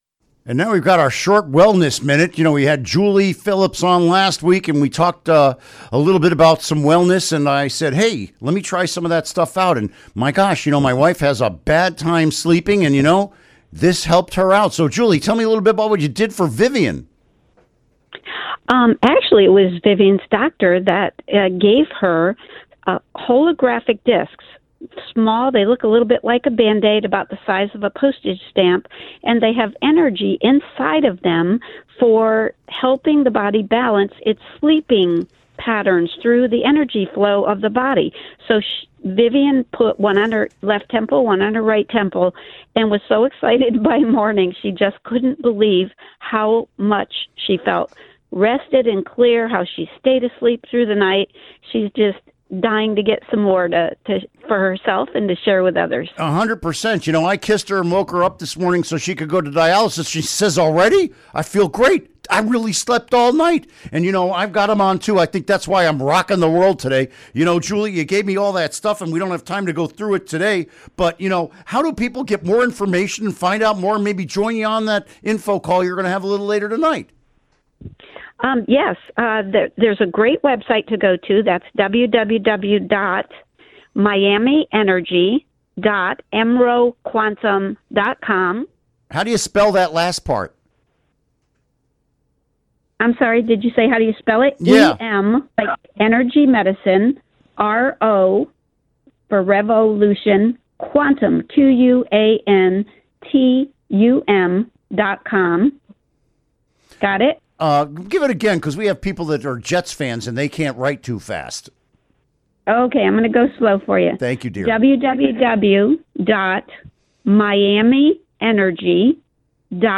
Interview Segment Episode 410: 03-09-17 (To download, right-click and select “Save Link As”.)